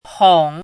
chinese-voice - 汉字语音库
hong3.mp3